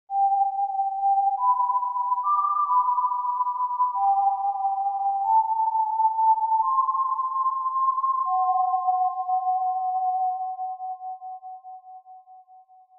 pib2-death_wistle.mp3